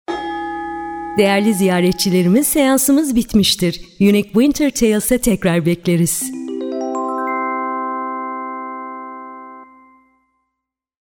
Versatile and expressive voice-over artist with a background in radio, music, and storytelling.
Clear diction, emotional range, and a warm, engaging tone – available in both English and Turkish.
Sprechprobe: eLearning (Muttersprache):